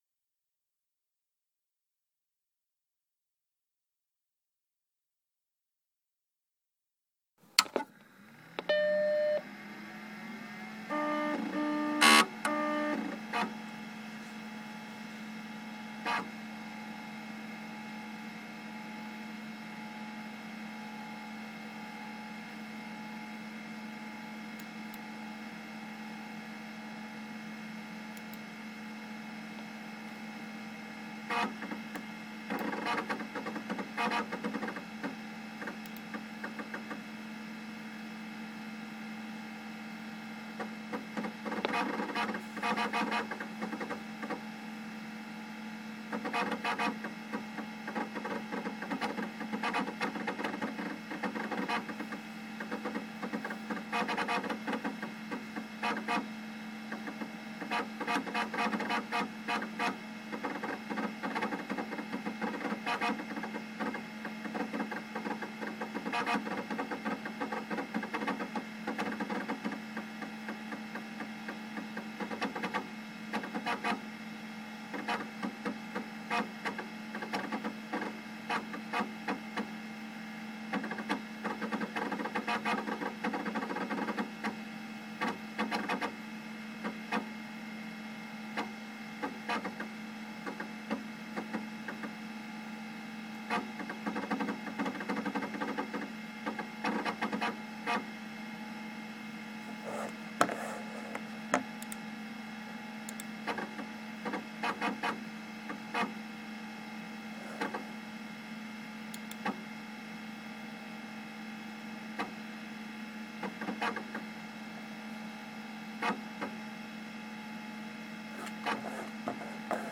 Then I realized it was the sound of the Mac SE working, with its characteristic grunts, and clunks, and whirs.